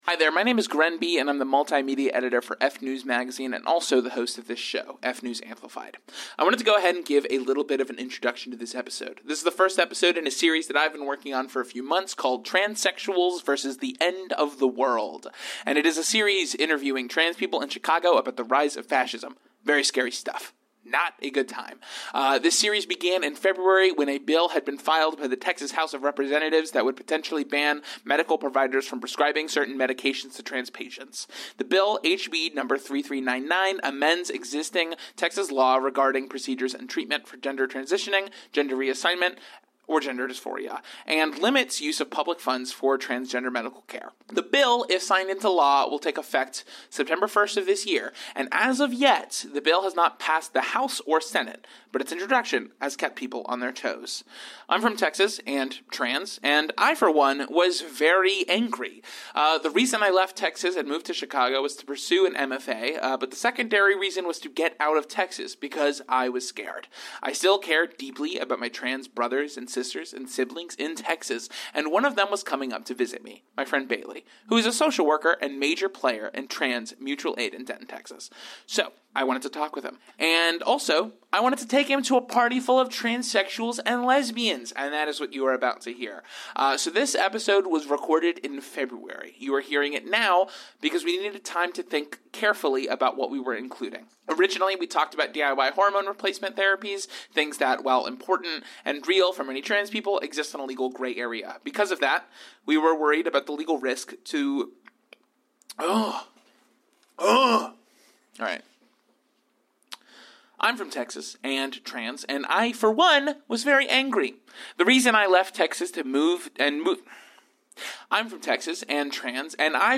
And it is a series interviewing trans people in Chicago about the rise of fascism.